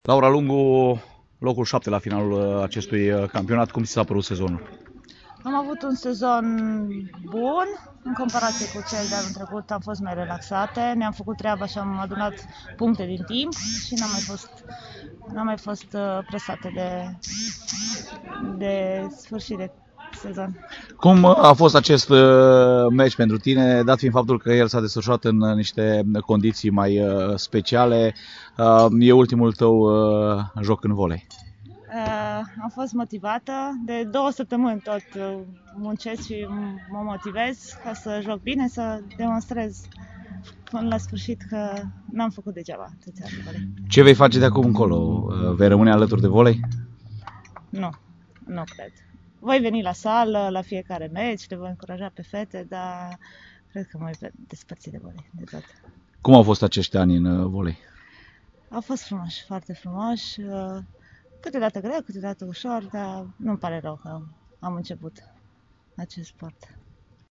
INTERVIU AUDIO